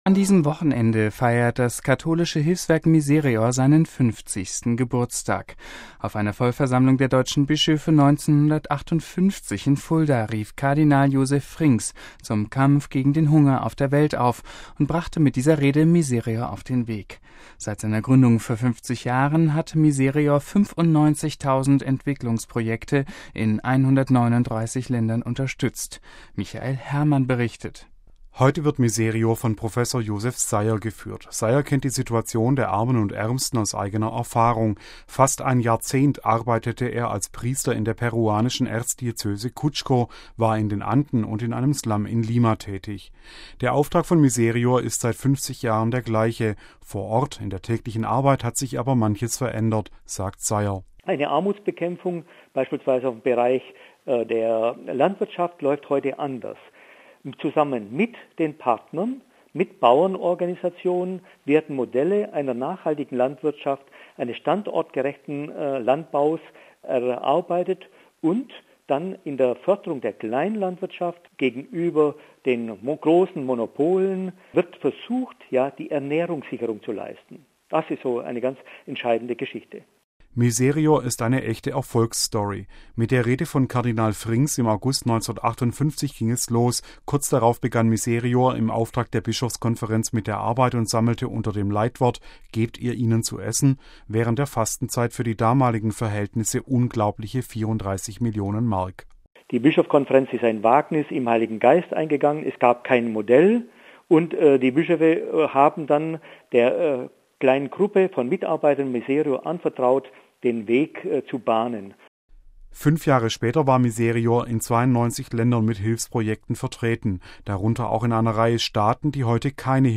Seit seiner Gründung vor 50 Jahre hat Misereor 95 000 Entwicklungsprojekte in 139 Ländern unterstützt. Ein Beitrag